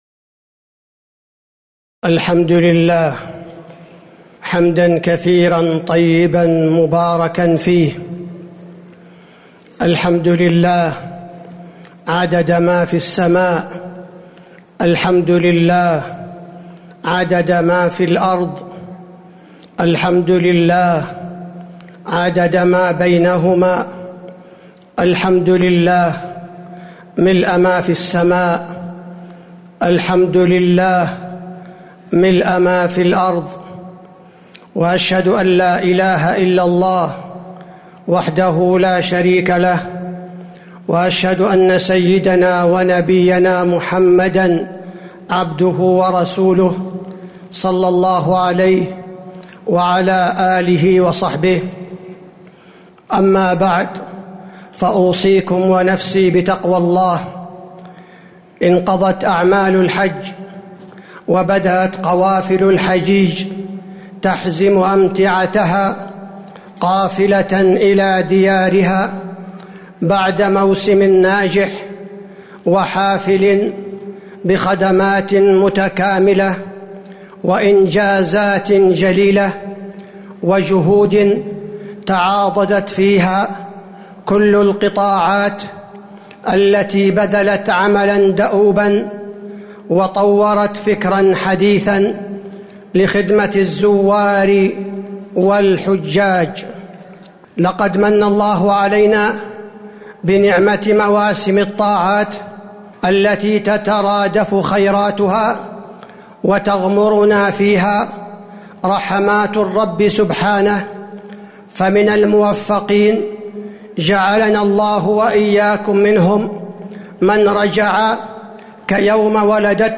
تاريخ النشر ١٦ ذو الحجة ١٤٤٣ هـ المكان: المسجد النبوي الشيخ: فضيلة الشيخ عبدالباري الثبيتي فضيلة الشيخ عبدالباري الثبيتي مواسم الطاعات